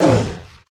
Minecraft Version Minecraft Version latest Latest Release | Latest Snapshot latest / assets / minecraft / sounds / mob / polarbear / hurt4.ogg Compare With Compare With Latest Release | Latest Snapshot